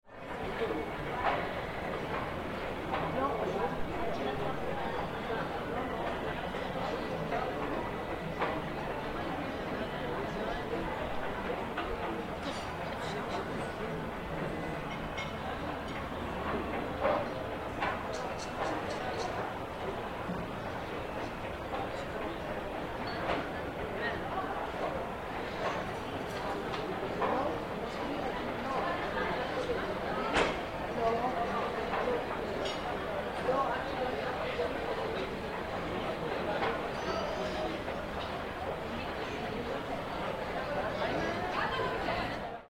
RESTAURANTE CAFE RESTAURANT EXTERIOR
EFECTO DE SONIDO DE AMBIENTE de RESTAURANTE CAFE RESTAURANT EXTERIOR
Restaurante_-_Cafe_restaurant_exterior.mp3